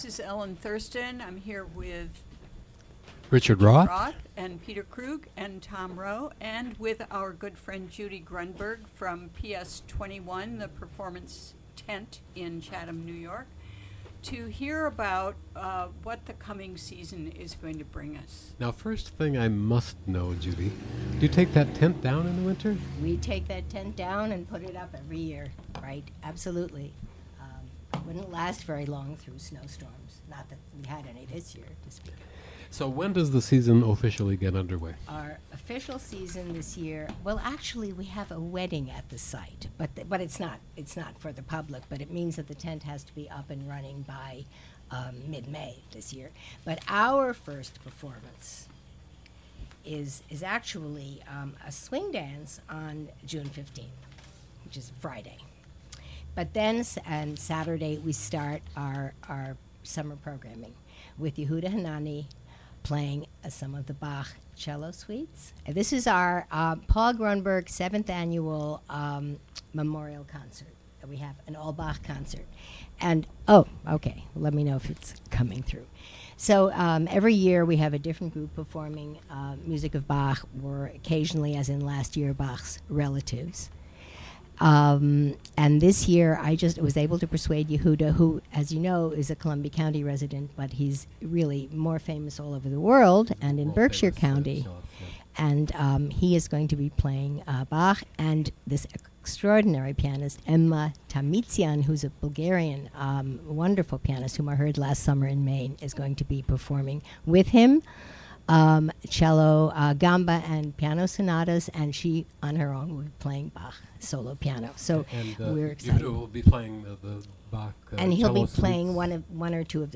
Interview on "WGXC Afternoon Show."